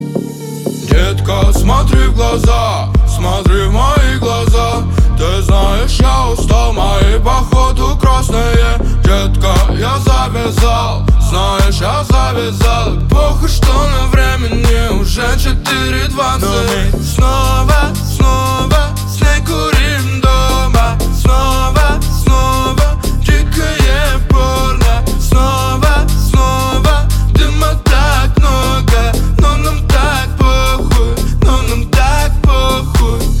• Качество: 320, Stereo
dance
club